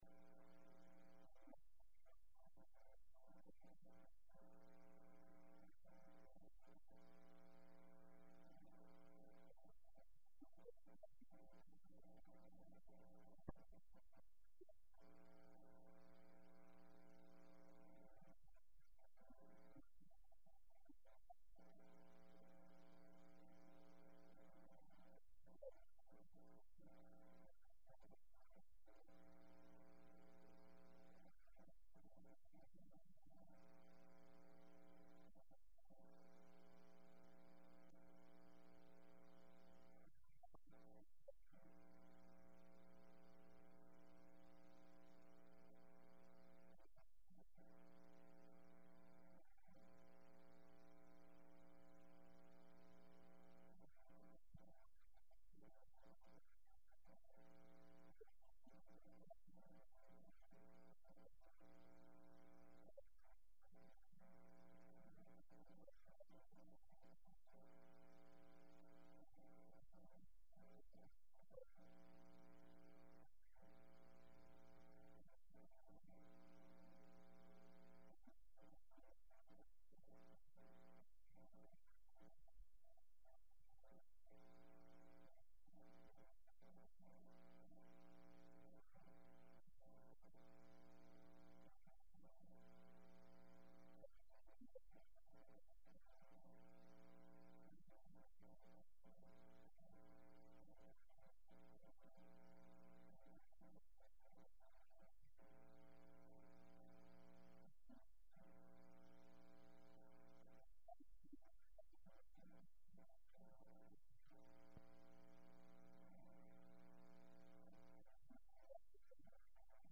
05/01/2011 Sunday Services
2011 Media Bible Study